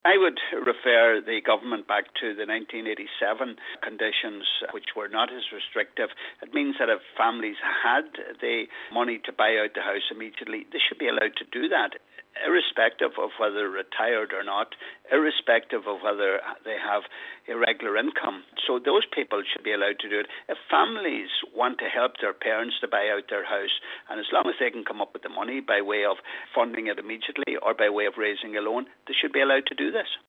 Deputy Gallagher is calling on the Government to review the current terms of the scheme and to make it more workable: